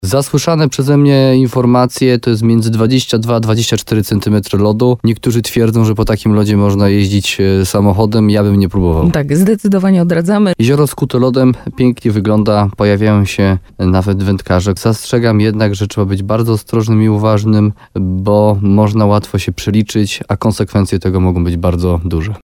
Gość programu Słowo za Słowo na antenie RDN Nowy Sącz dodał, że warstwa lodu jest gruba, ale jednocześnie apelował o zachowanie tu zasad bezpieczeństwa.